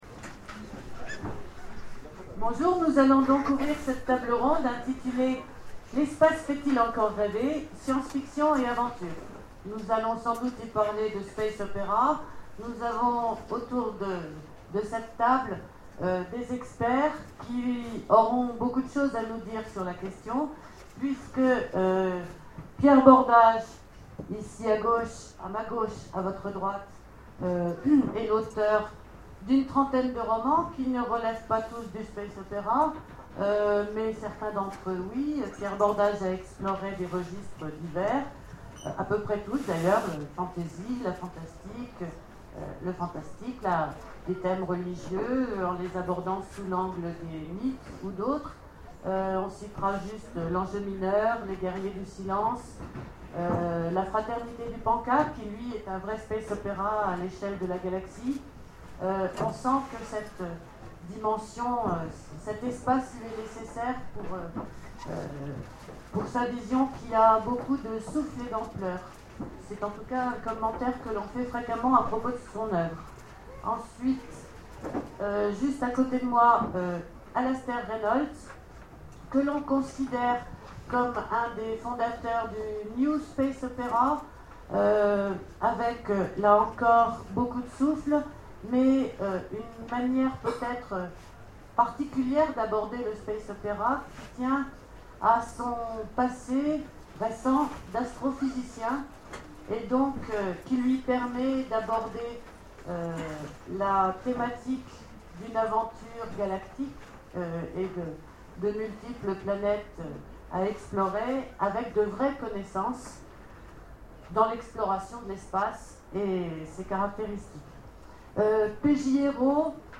Imaginales 2013 : Conférence L'espace fait-il encore rêver ?